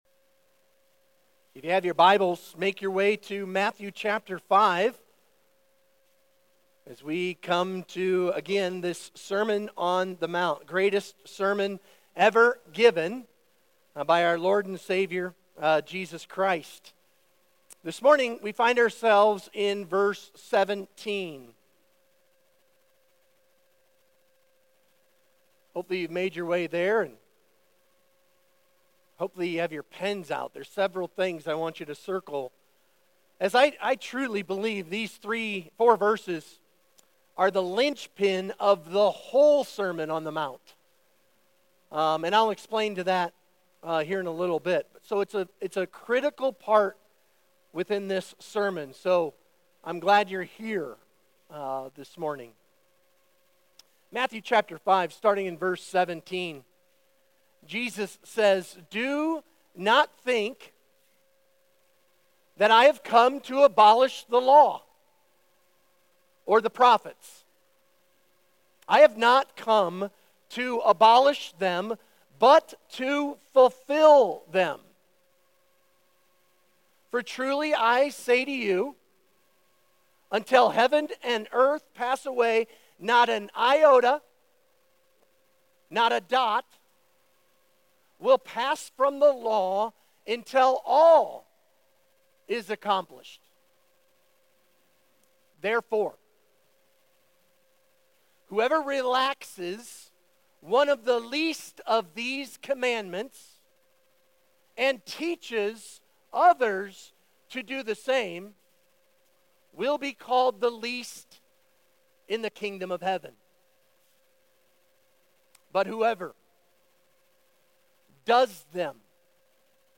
Sermon Questions Read Matthew 5:17-20.